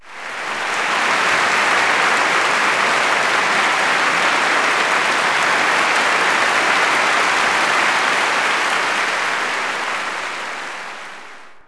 clap_038.wav